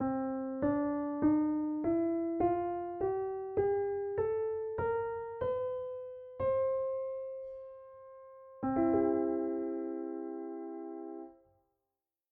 BlackwoodMajor_15edo.mp3